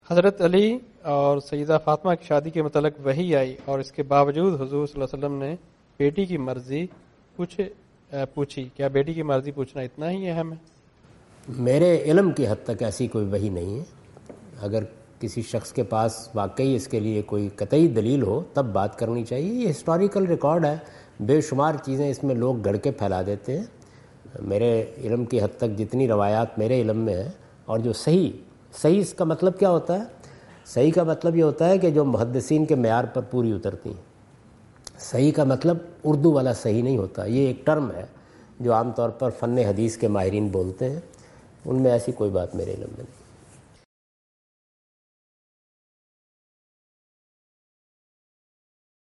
Javed Ahmad Ghamidi answer the question about "daughter's consent for marriage" in Macquarie Theatre, Macquarie University, Sydney Australia on 04th October 2015.
جاوید احمد غامدی اپنے دورہ آسٹریلیا کے دوران سڈنی میں میکوری یونیورسٹی میں "شادی کے لیے بیٹی کی رضا مندی" سے متعلق ایک سوال کا جواب دے رہے ہیں۔